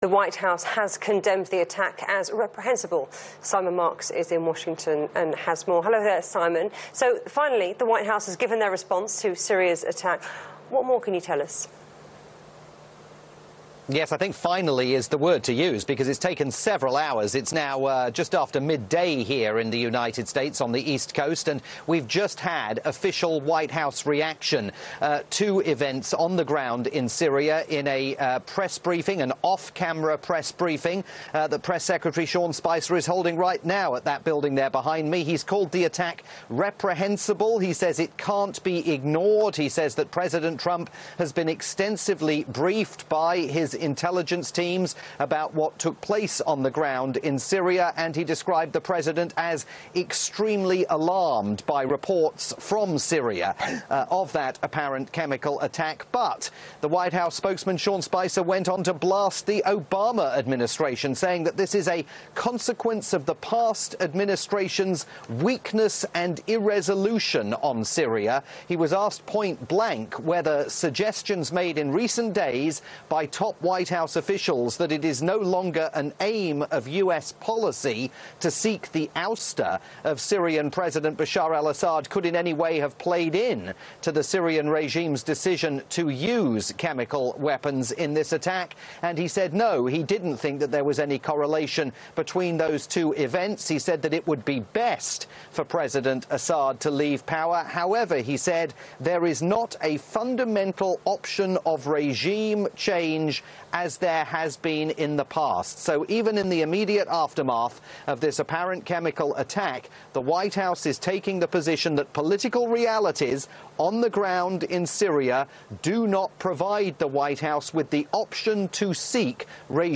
Via Turkey's international TV news channel TRT World.